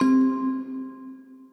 Bell - Mystic.wav